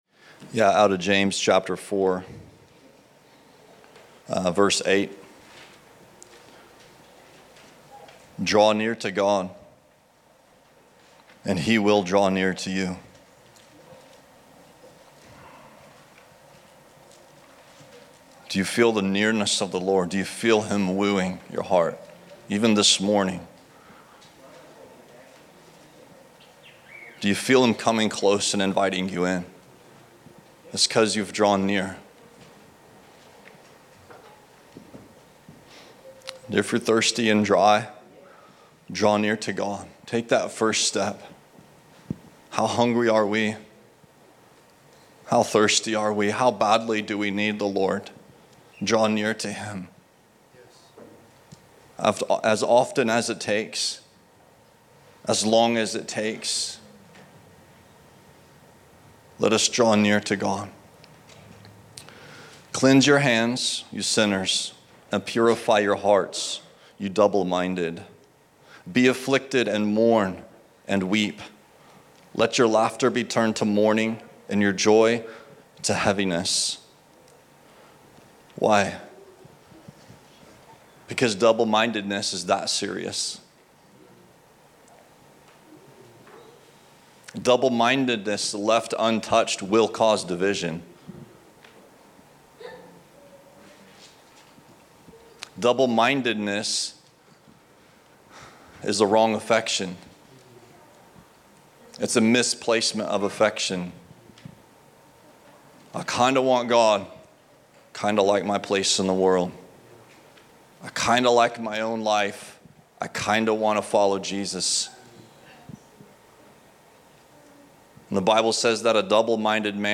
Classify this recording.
Category: Sermons